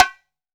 Index of /90_sSampleCDs/AKAI S6000 CD-ROM - Volume 5/Cuba2/STEREO_BONGO_2